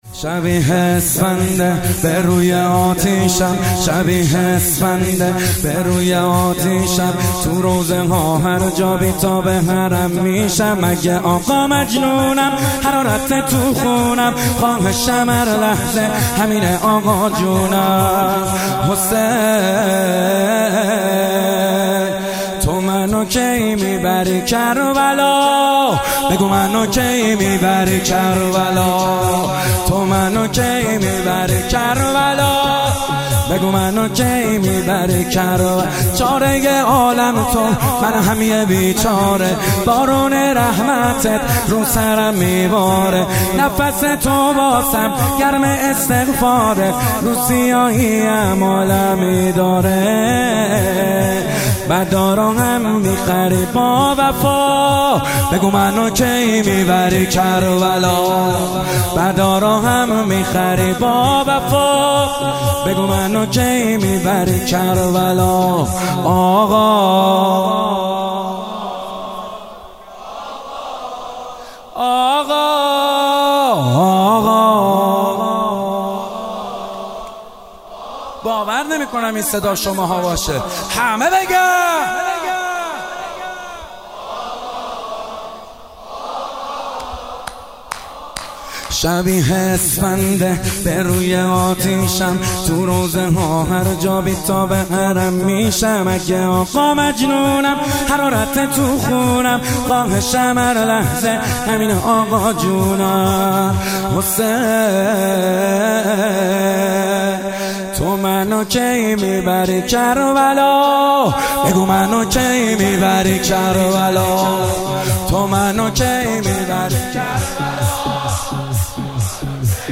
شب چهارم محرم 98 با نوای کربلایی محمد حسین حدادیان
متن نوحه